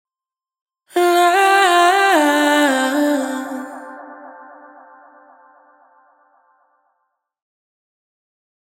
Her warm, deep, and soulful vocals bring profound emotion to everything they touch.
• 57 Unique vocal adlibs (Oohs and Aaahs) – dry and wet.